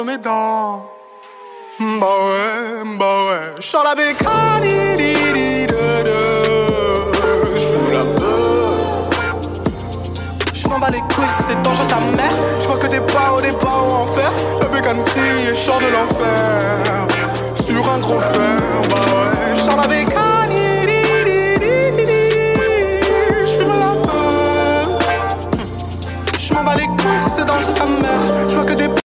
2024-02-14 20:00:08 Gênero: Trap Views